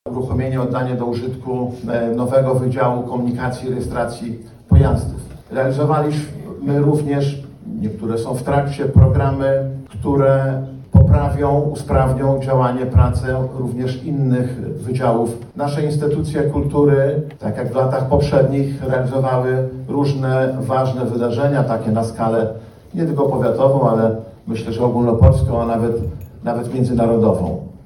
Występy młodzieży z Regionalnego Ośrodka Kultury, wspólne śpiewanie kolęd, rozmowy i moc życzeń – tak wyglądało spotkanie wigilijne, które odbyło się w piątek (20.12) w Starostwie Powiatowym w Łomży.